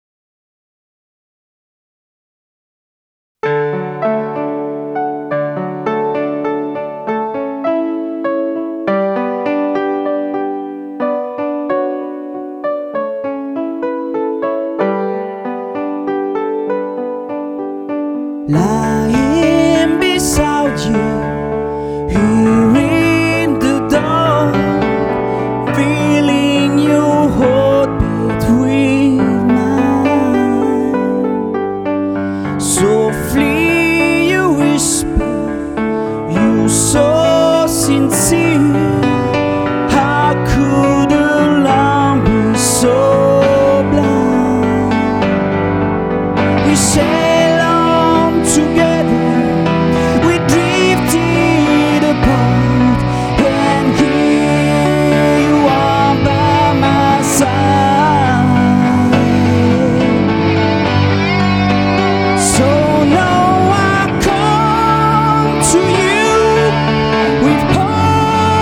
Studio Côtier, Frontignan, France.
Guitare
Chant, Choeurs
Basse